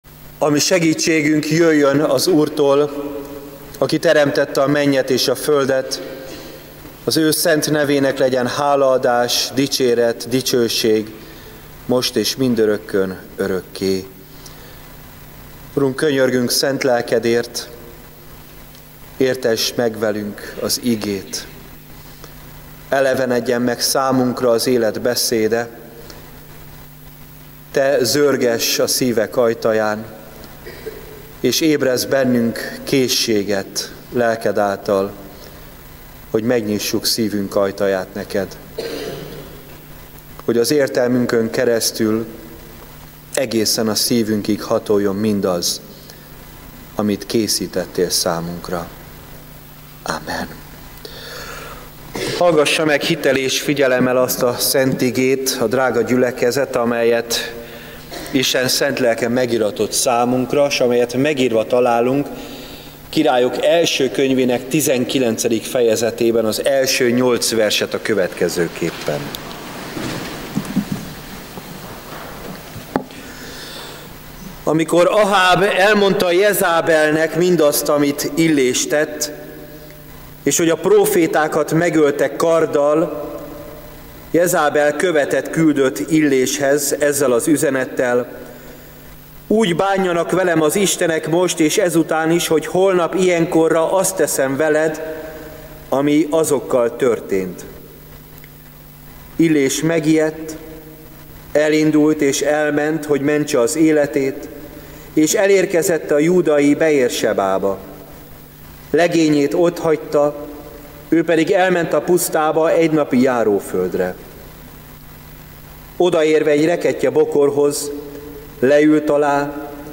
Igehirdetések – Nyíregyházi Evangélikus Egyházközség